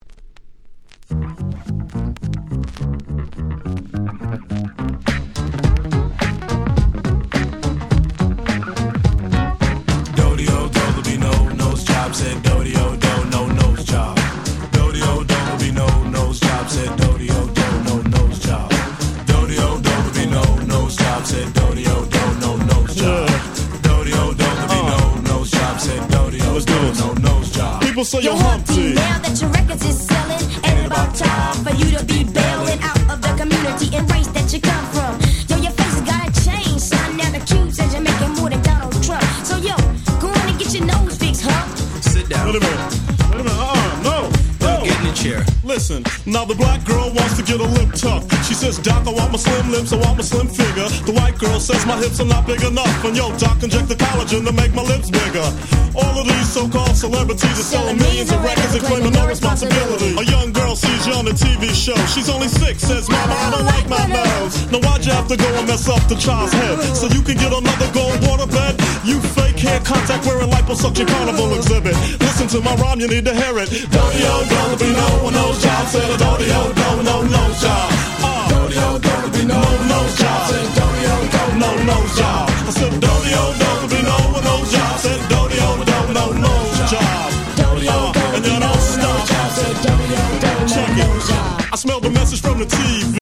92' Smash Hit Hip Hop !!
90's デジタルアンダーグラウンド Boom Bap ブーンバップ